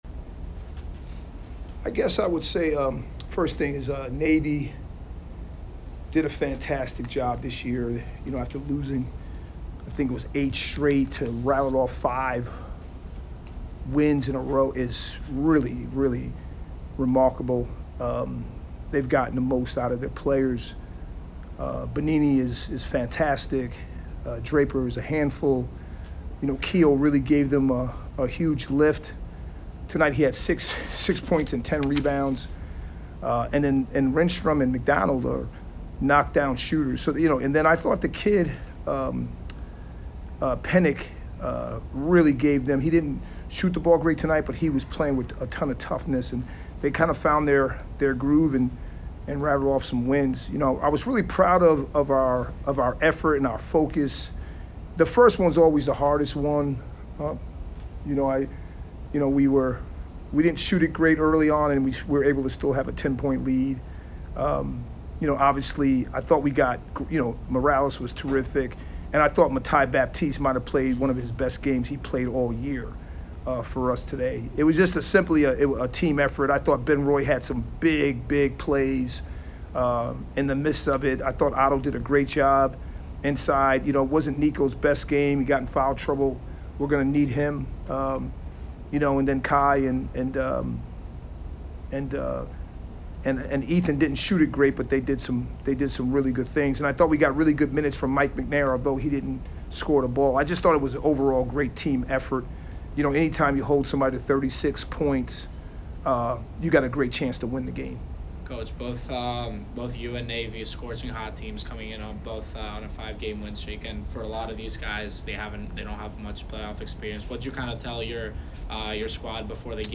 Men's Basketball / Navy Postgame Interview